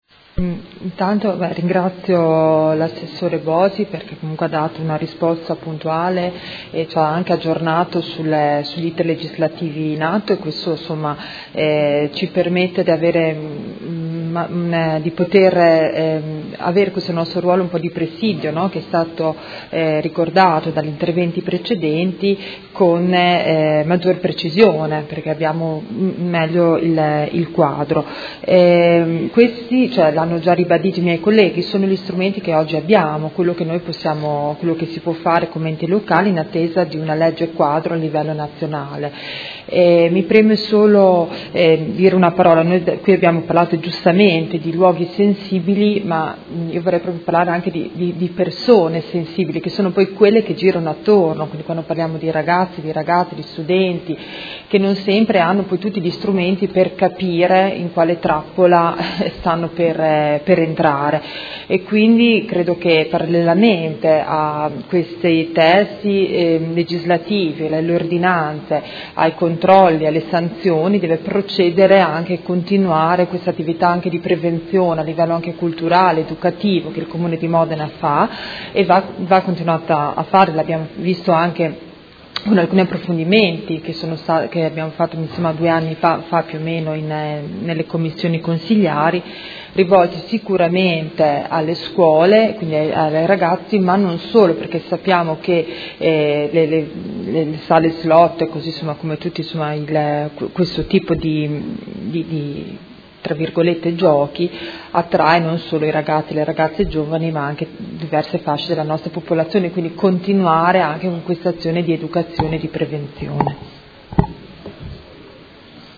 Seduta del 27/04/2017 Replica a risposta Assessore. Interrogazione dei Consiglieri Baracchi e Poggi (PD) avente per oggetto: Apertura Sala Slot c/o piano terra Palazzo Europa.